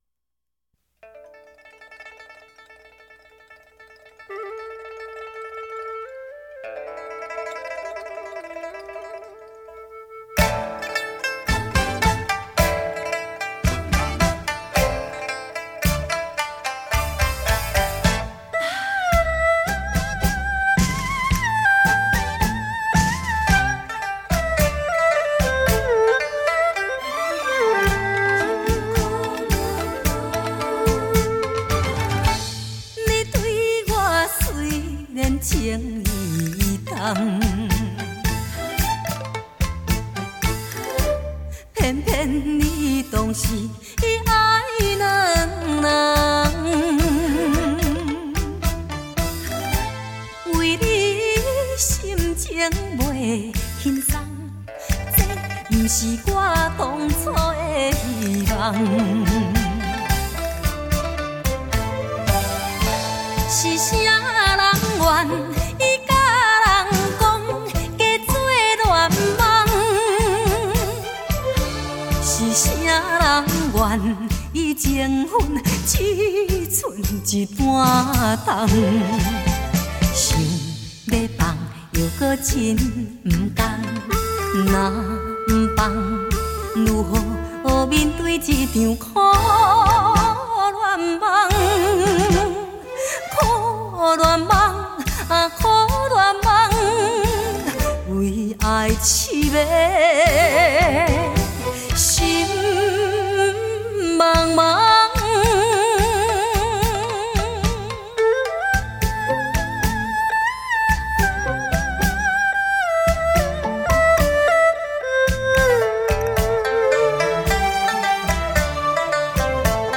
金曲奖最佳女歌手
闽南语伤感情歌